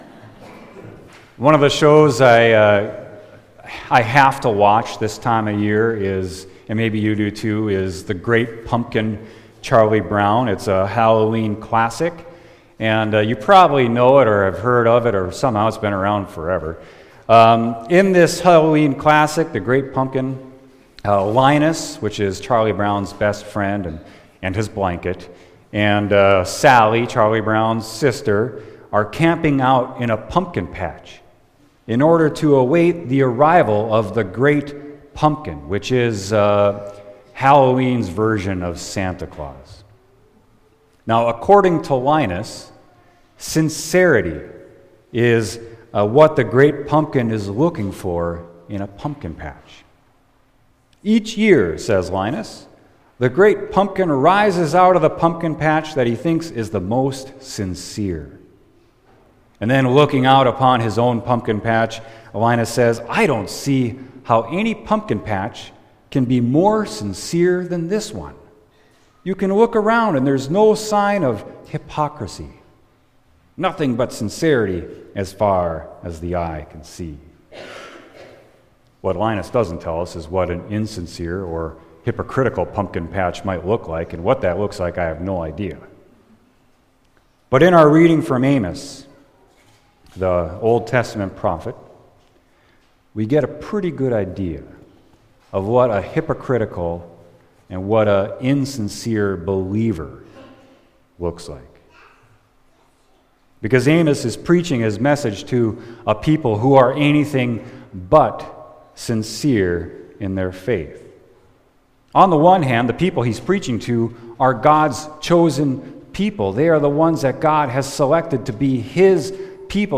Sermon: Amos 5.18-24